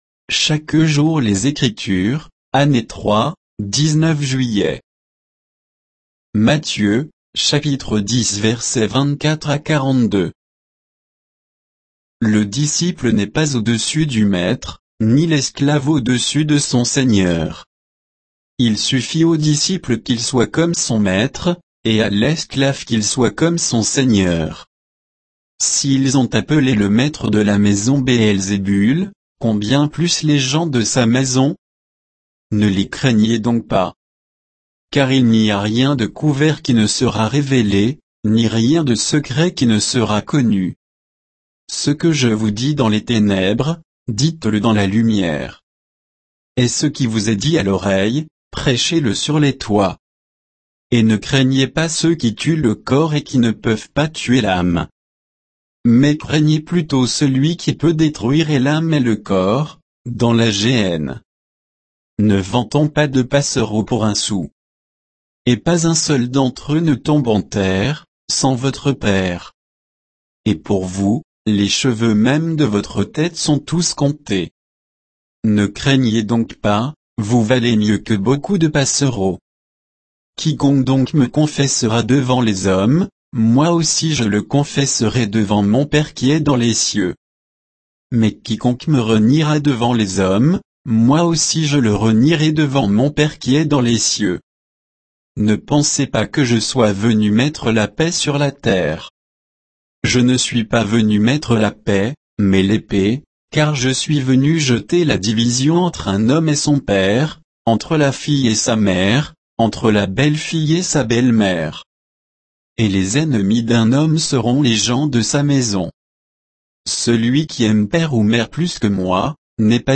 Méditation quoditienne de Chaque jour les Écritures sur Matthieu 10